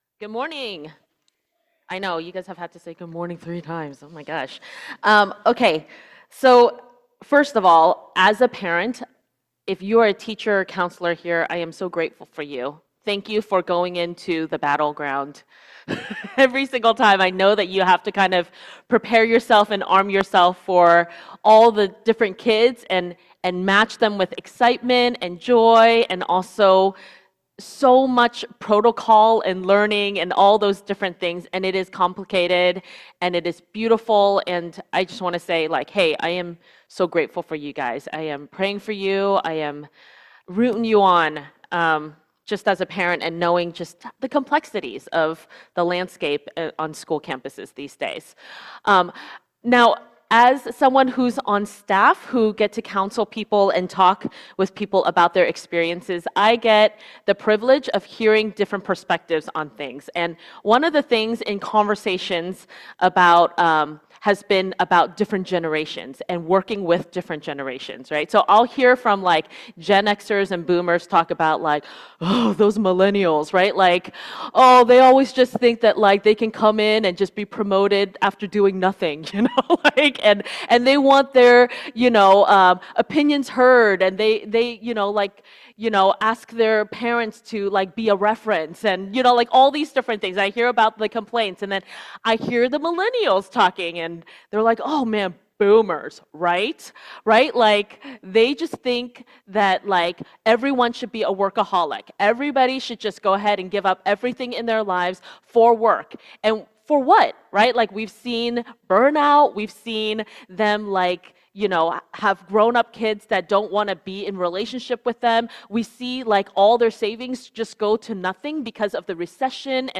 Sermons | Missio Community